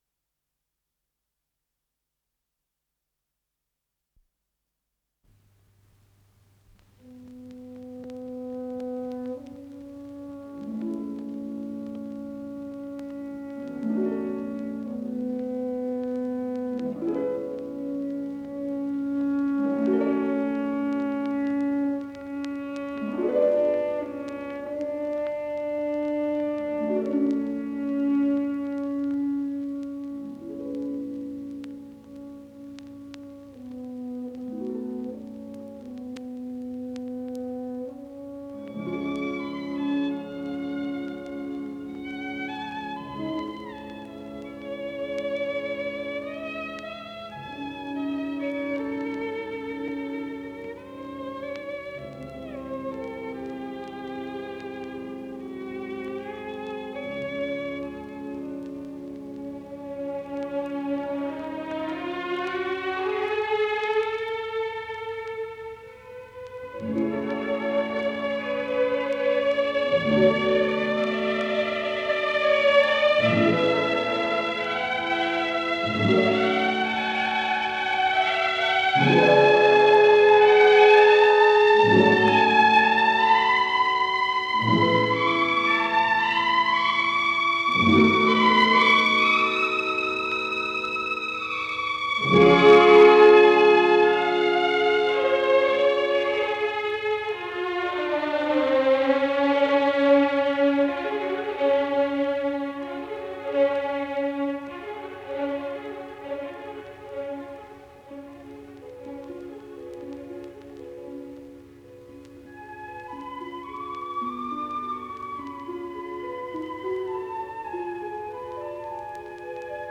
Исполнитель: Филадельфийский симфонический оркестр
Ля минор